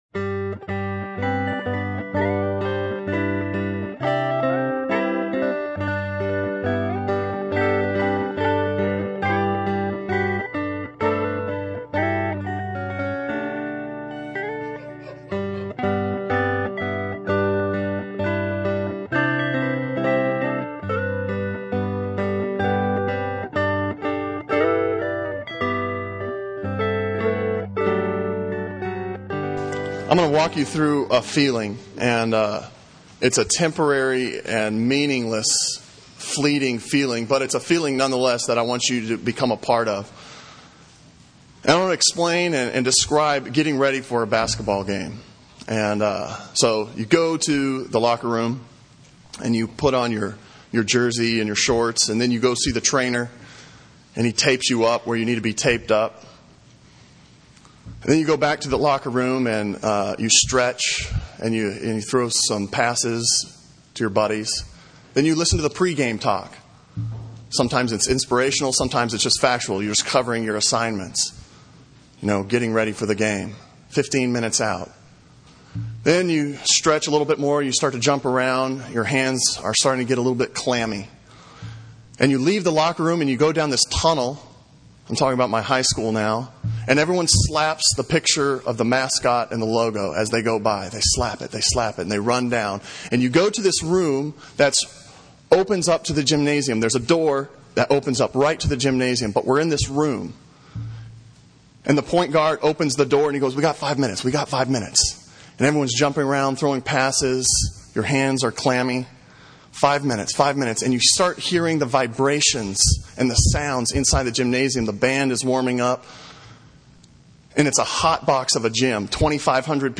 « Back to sermons page Breaking and Entering Sermon from April 1